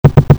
cartoon34.mp3